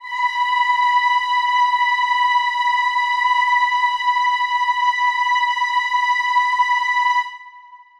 Choir Piano (Wav)
B5.wav